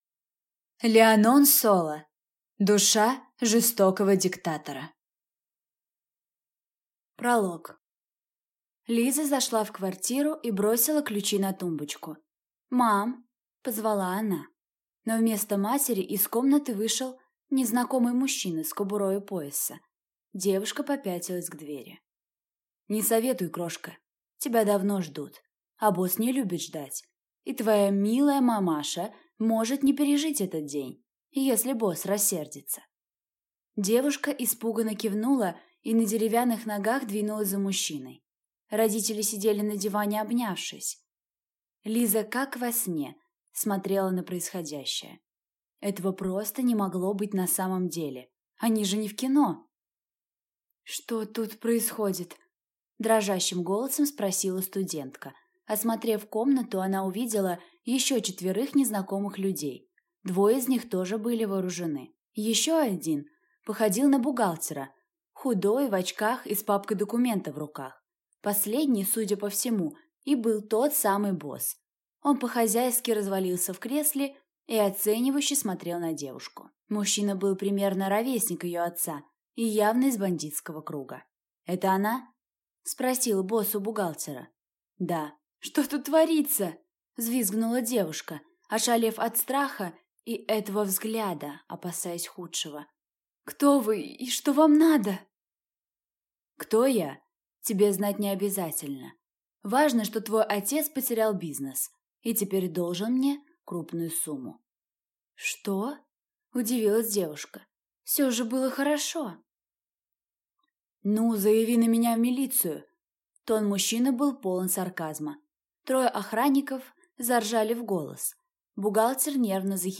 Аудиокнига Душа жестокого диктатора | Библиотека аудиокниг